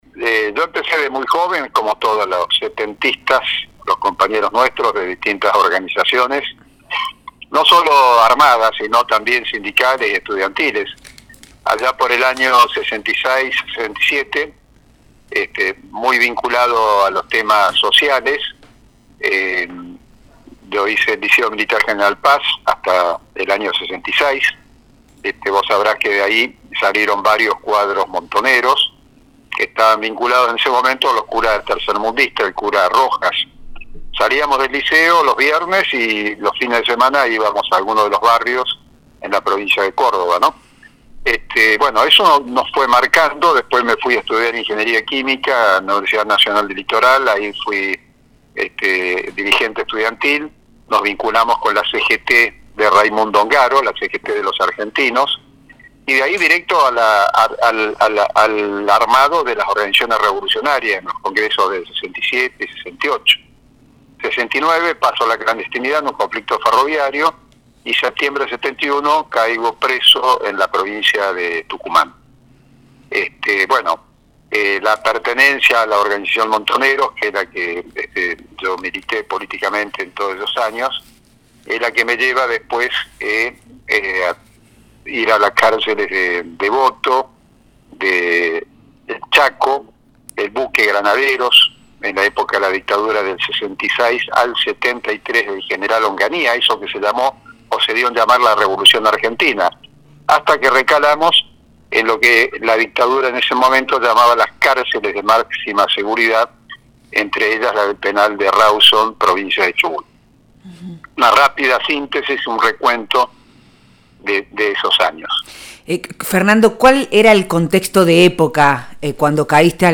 Entrevista a Fernando Vaca Narvaja El 15 de agosto de 1972, durante la dictadura de Alejandro Lanusse, veinticinco presas y presos políticos de diversas organizaciones se fugaron del penal de máxima seguridad de Rawson con […]
Nosotres les Otres: jueves de 18 a 20 por FM Horizonte, 94.5